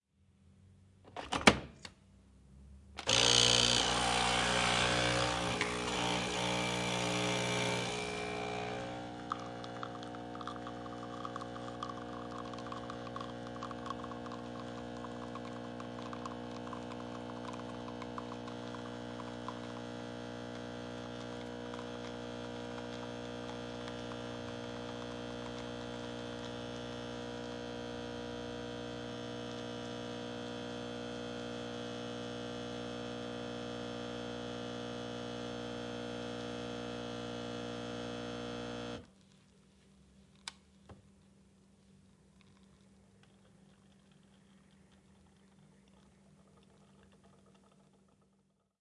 MaquinadecaféNespresso咖啡厅